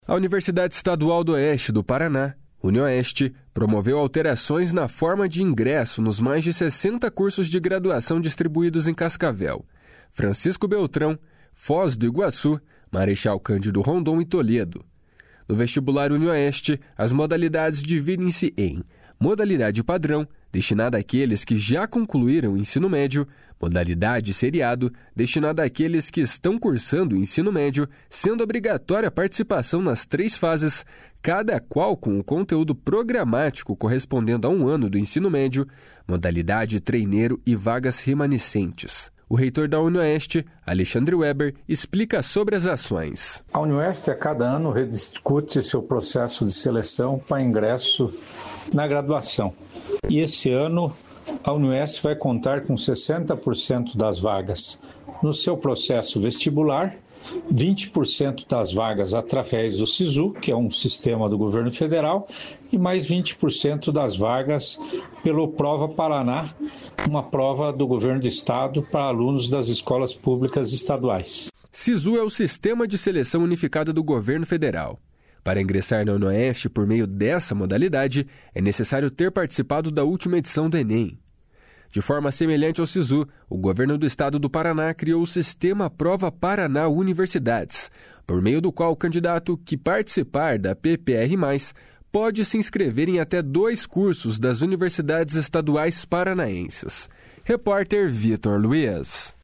O reitor da Unioeste, Alexandre Webber, explica sobre as ações. // SONORA ALEXANDRE WEBBER //